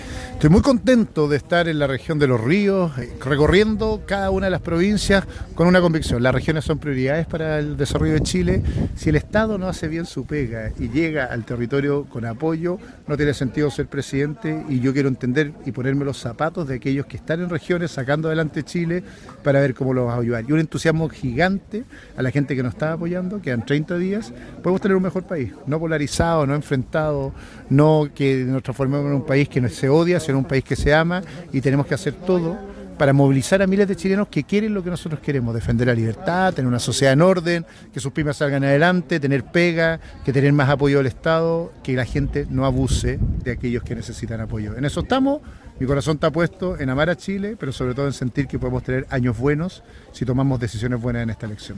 Una de las comunas escogidas fue Río Bueno, donde participó en un encuentro con vecinos de la comuna y se reunió con candidatos a senadores y diputados, así como también, con alcaldes de la provincia del Ranco.
CUÑA-2-SICHEL-RANCO.mp3